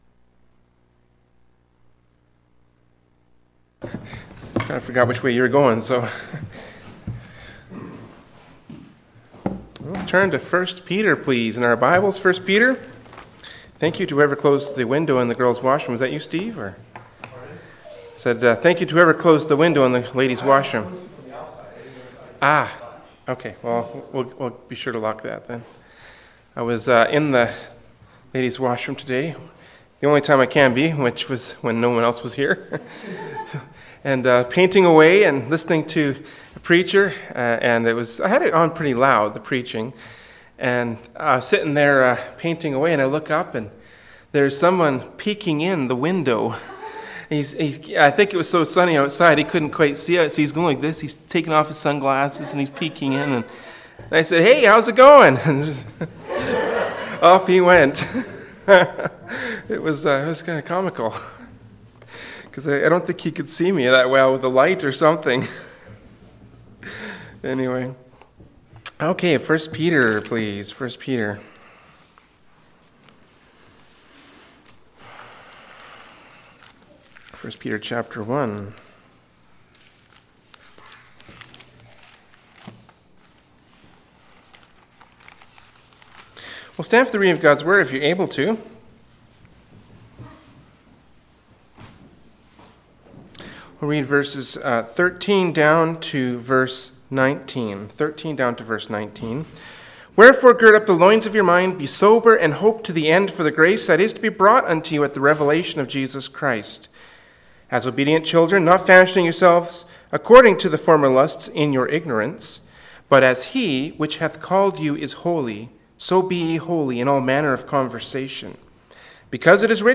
“1st Peter 1:13-19” from Wednesday Evening Service by Berean Baptist Church.